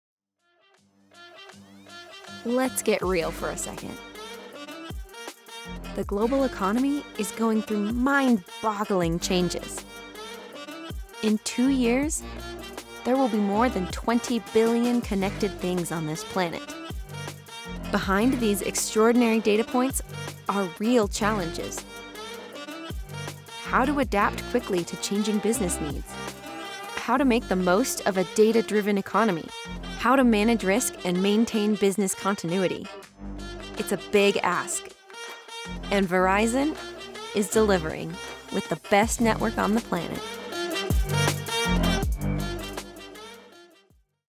Verizon Network Delivers - Commercial Demo
I do voiceover full time from a professionally treated in-home studio using a Sennheiser MKH 416 microphone and Apollo Twin X Duo interface.
I was born just outside Seattle Washington, which means I offer that neutral accent that can be used nation wide.
Friendly, conversational, girl next door